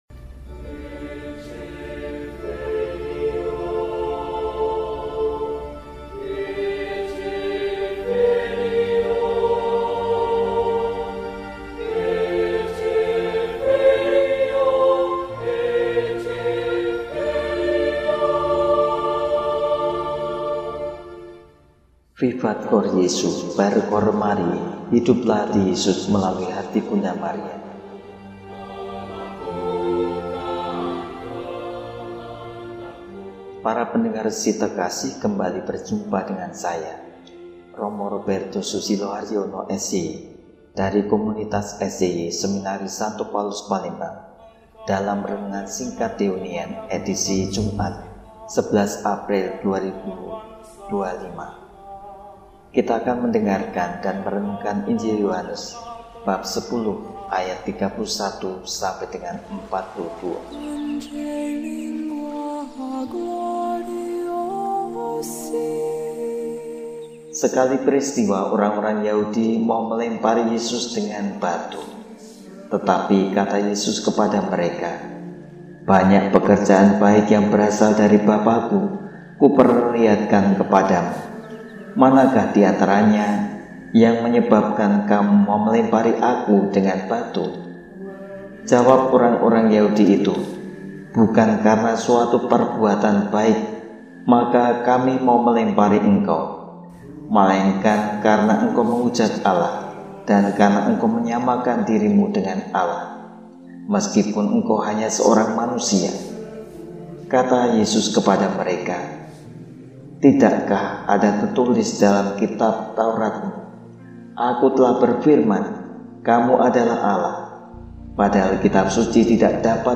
Jumat, 11 Maret 2025 – Hari Biasa Pekan V Prapaskah – RESI (Renungan Singkat) DEHONIAN